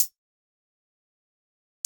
HiHat (Glow).wav